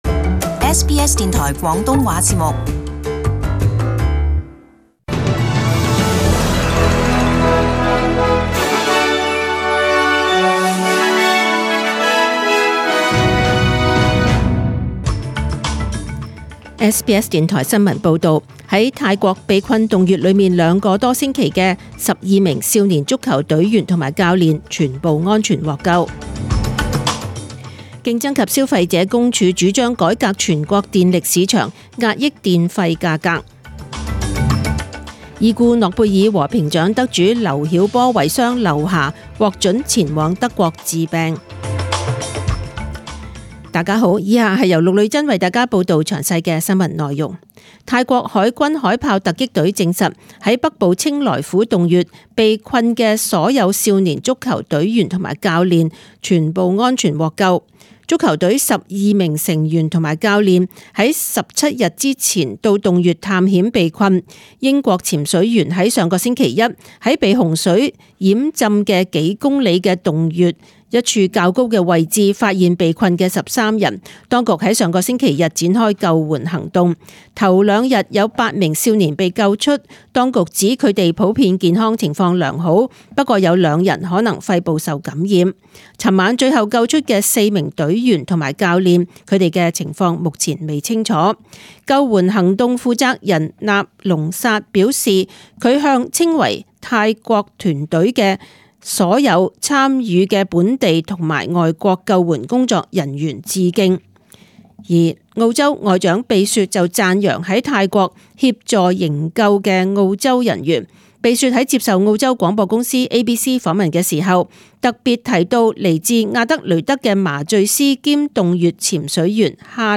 SBS中文新闻 （七月十一日)
请收听本台为大家准备的详尽早晨新闻。